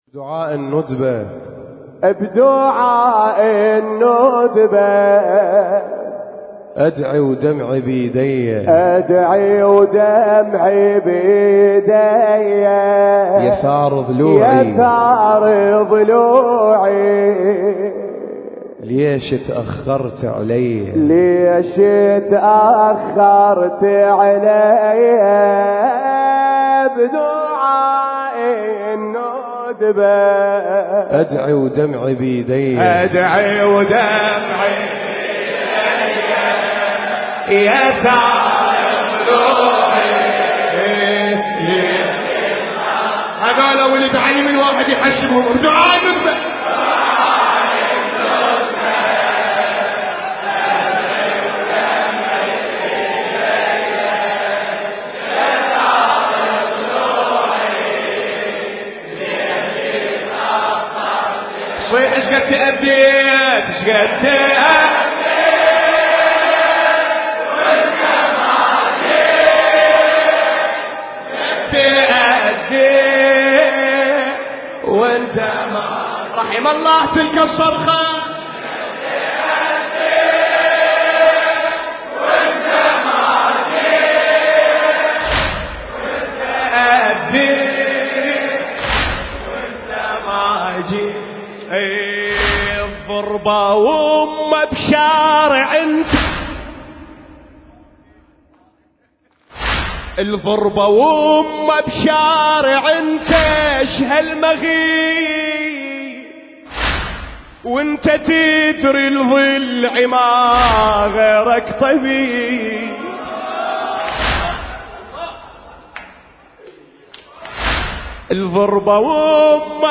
الحجم: 5.36 MB الشاعر: ميثاق الحمداني المكان: حسينية بيت الأحزان - النجف الأشرف التاريخ: ذكرى شهادة السيدة فاطمة الزهراء (عليها السلام) - 1442 للهجرة